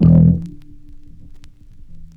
F_07_Bass_01_SP.wav